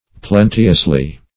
[1913 Webster] -- Plen"te*ous*ly, adv. --